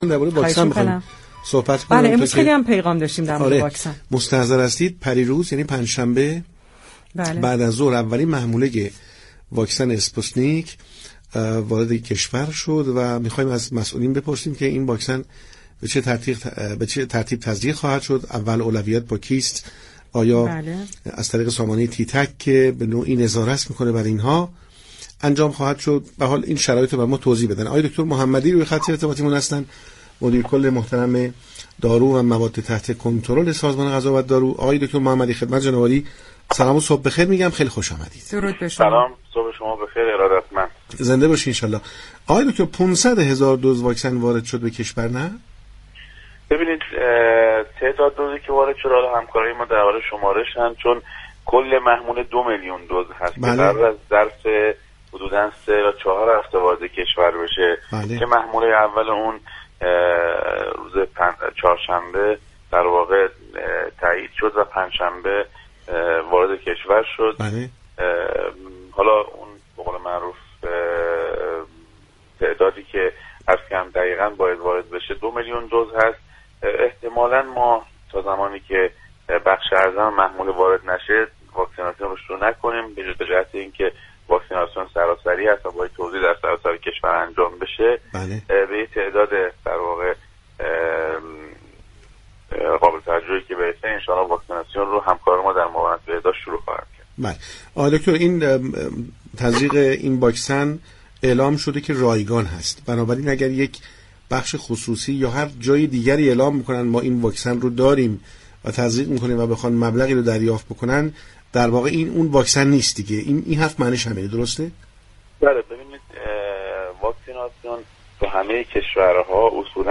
حیدر محمدی در گفتگو با برنامه پارك شهر 18 بهمن ماه درباره تعداد واكسن های وارداتی اظهار داشت: كل محموله دو میلیون دوز است كه قرار است طی سه تا چهار هفته آینده وارد كشور شود.